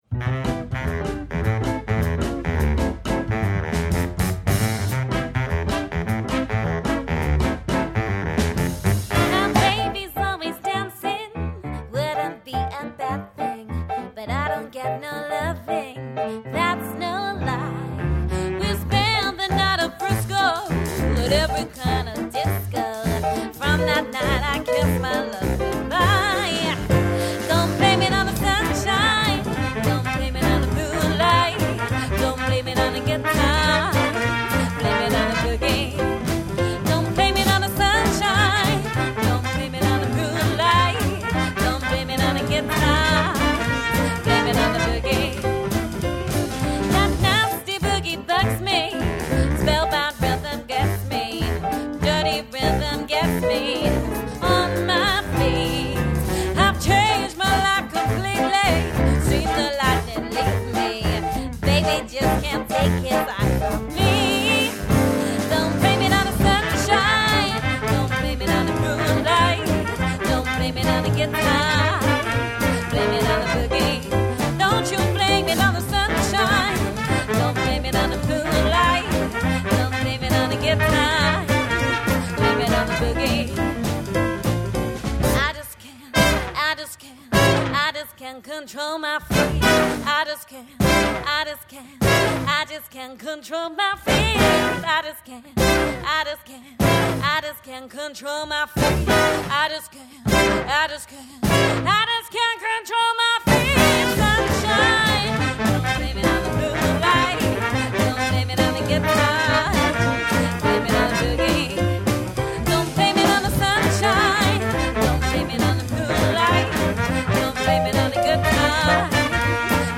unique vintage-style arrangements
• Jazz/Pop Crossover
Vocals, Bass, Drums, Keys, Trumpet, Sax, Guitar, Trombone